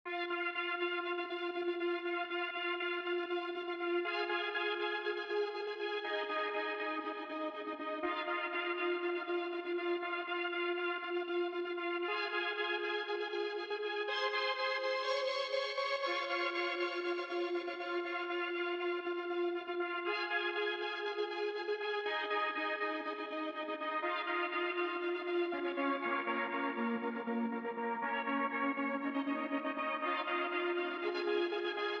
13 seq synth B.wav